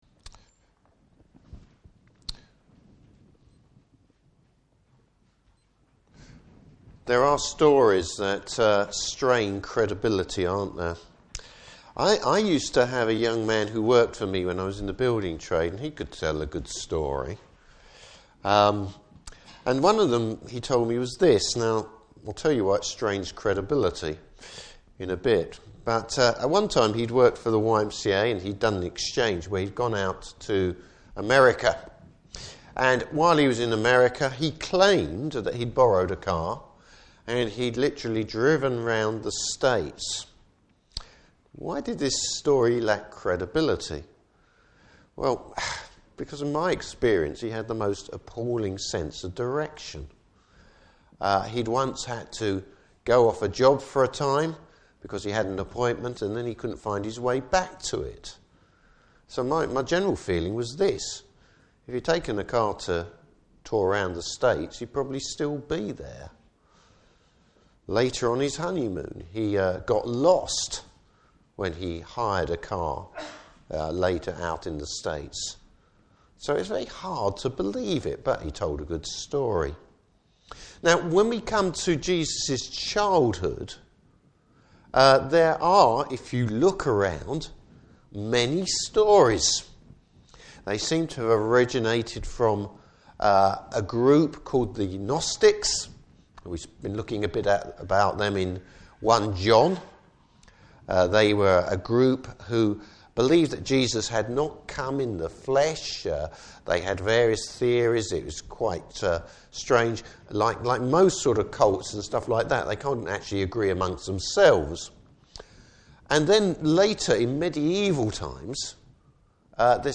Service Type: Morning Service Bible Text: Luke 2:41-52.